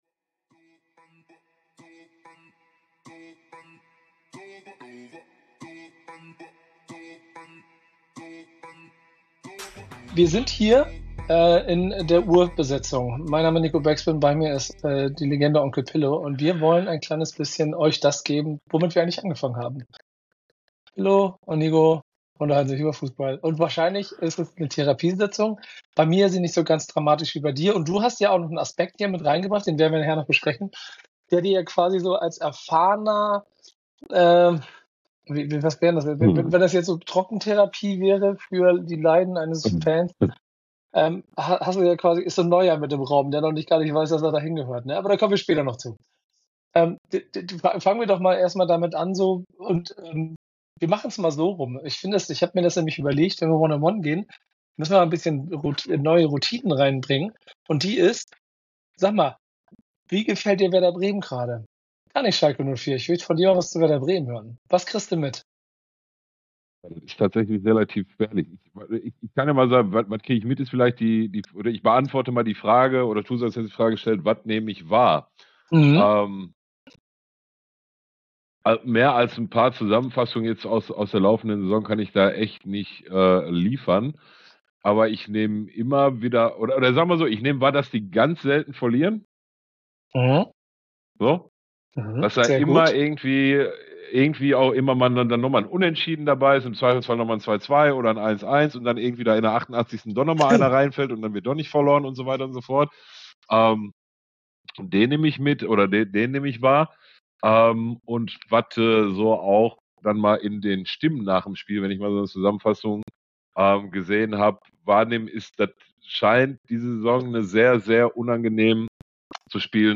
Die beiden sprechen über ihre jeweiligen Herzensvereine Werder Bremen bzw. Schalke 04 und resümieren über die bisherige Saison.